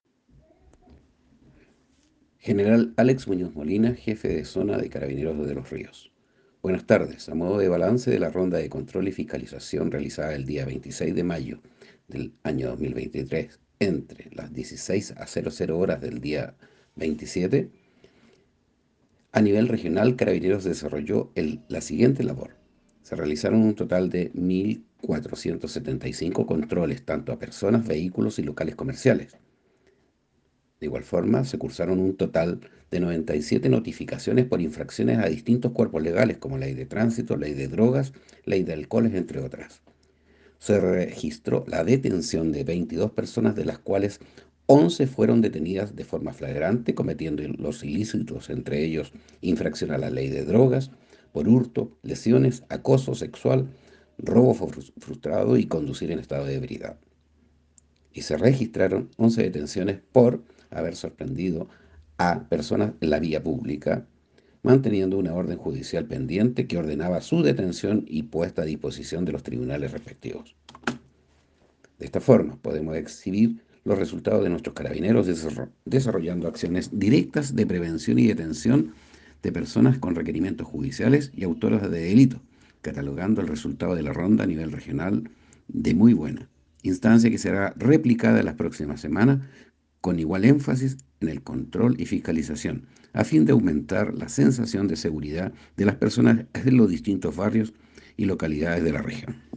Voz del General de zona.